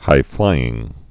(hīflīĭng)